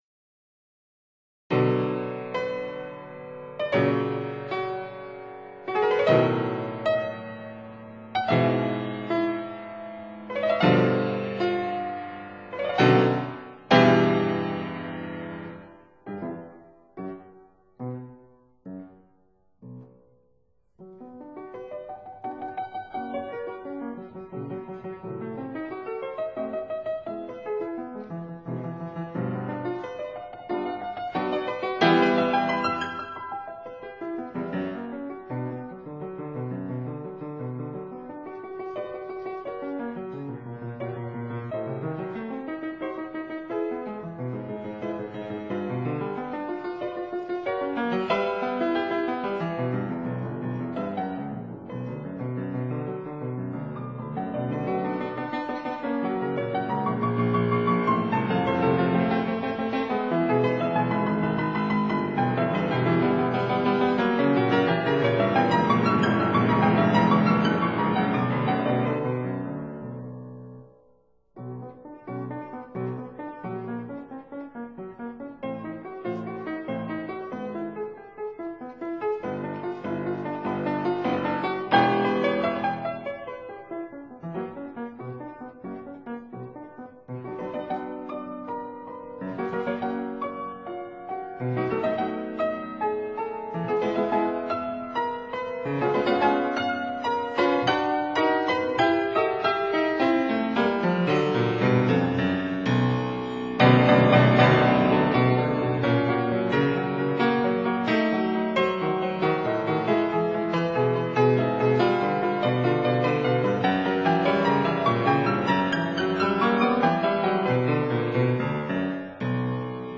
Fortepiano (Period Instruments)